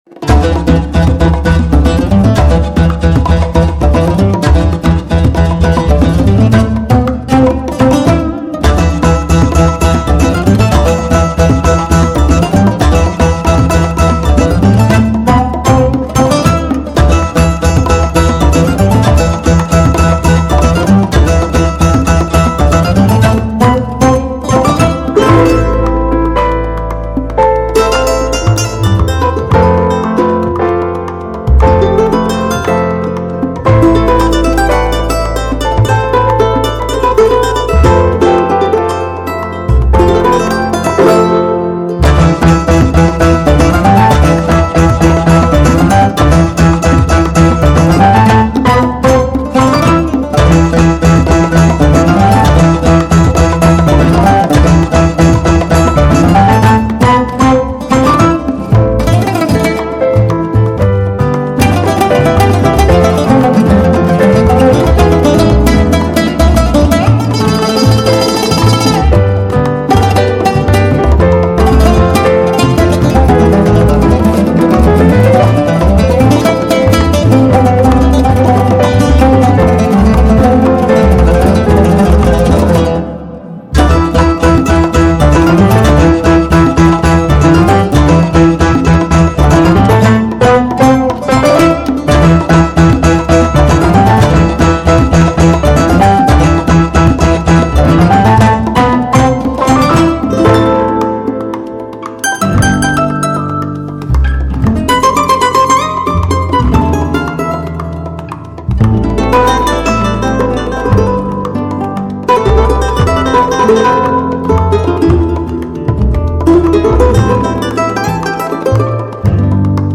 con el charango como instrumento principal.